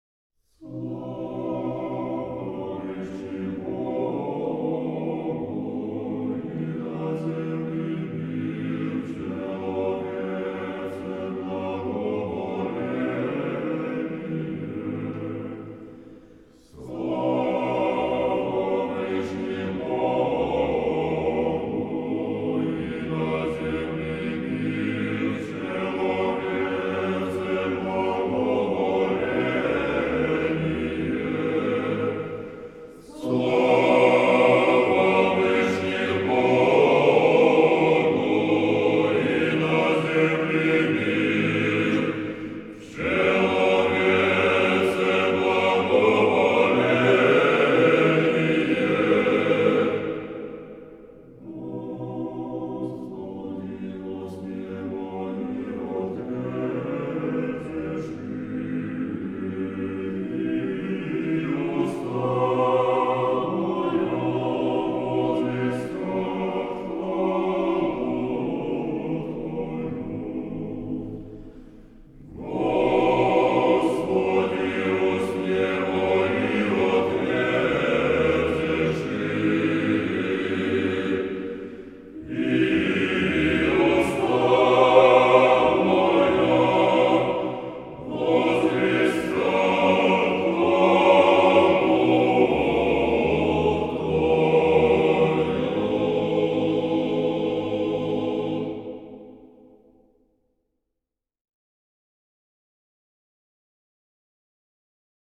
Прослушивание песнопения «Слава в вышних Богу» на церковнославянском языке в исполнении хора «Валаам».